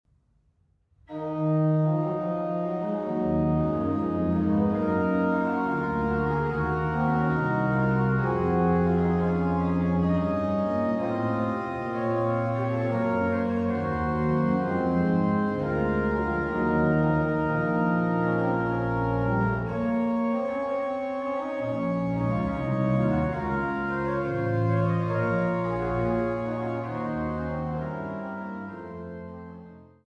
à 2 Clav. et Ped.